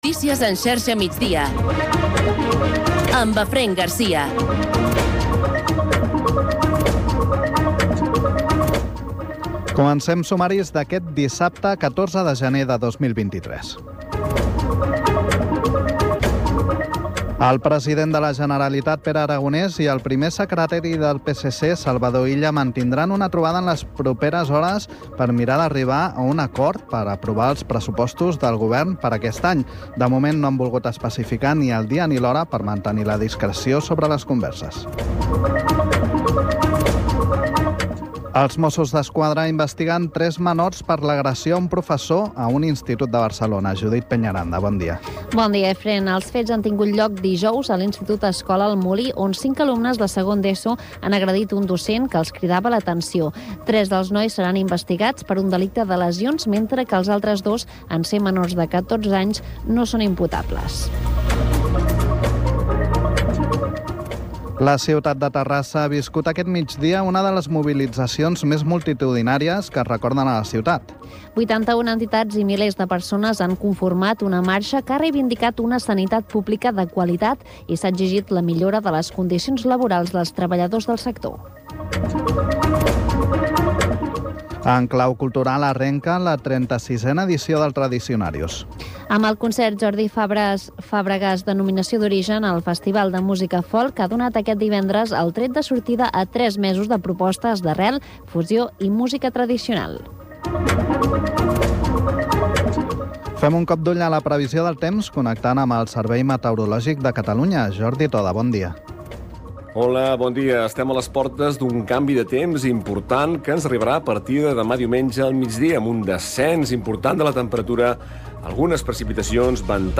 Informatiu radiofònic que dóna prioritat a l’actualitat local.